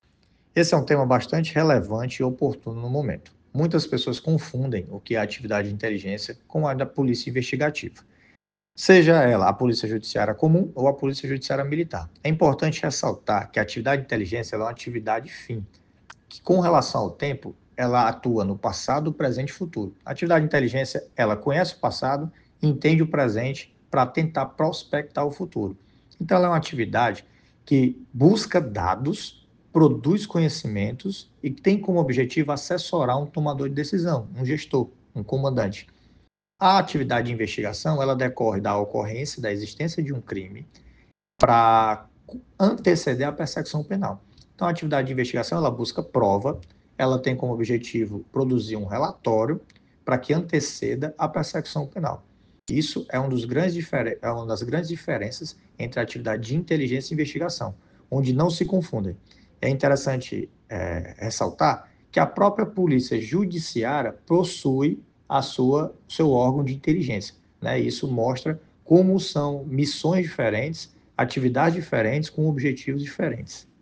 Em entrevista por áudio à Assessoria de Comunicação da PMCE